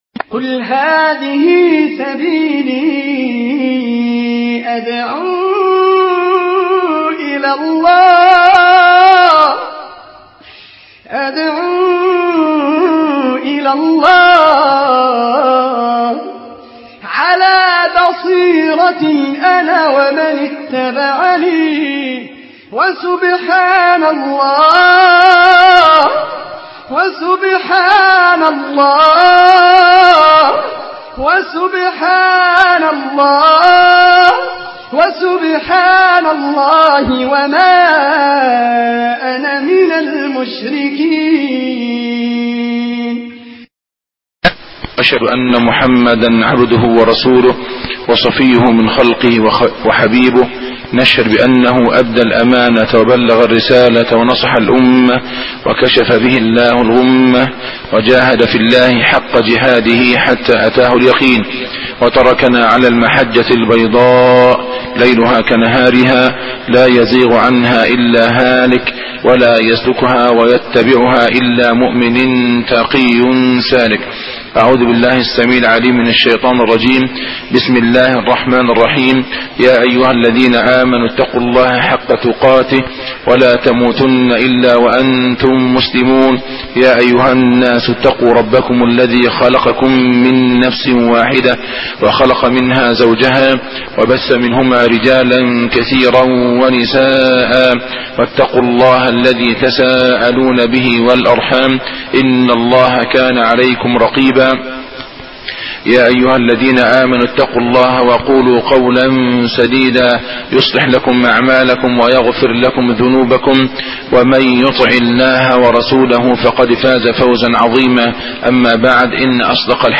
الدرس 1 ( السحر وعالم الجن